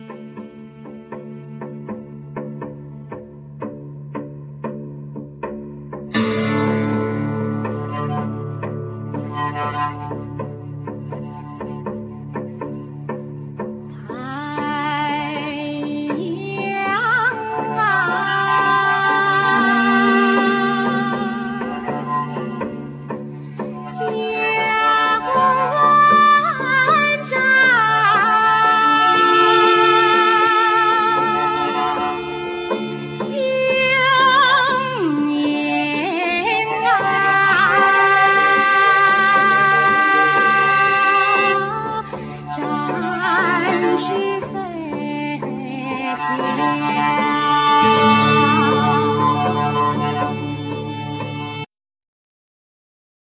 Voclas
Violin,Bariton violin,Viola
Guiatr,Mandlin,Cello,Percussions,Zither,Kobala,Vocals
Piano,Cembalo,Guitar
Percussion,Drums